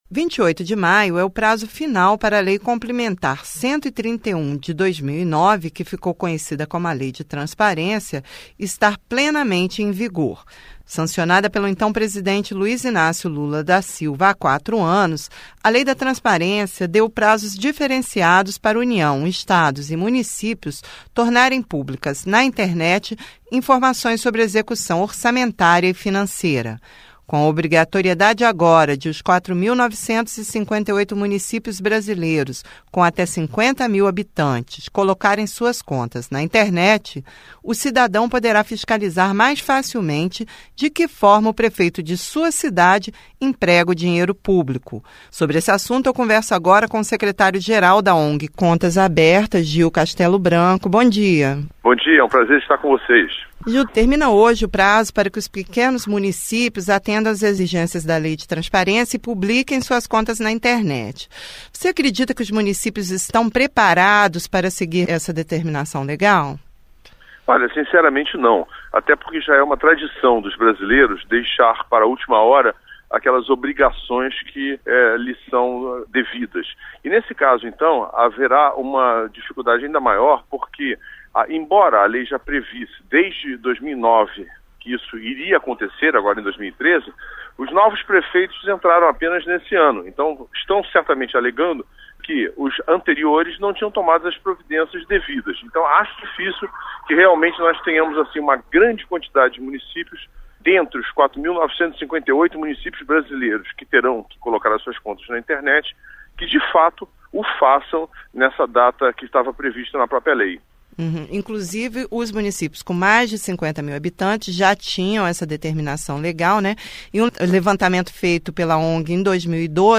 Entrevista: Divulgação de contas de prefeituras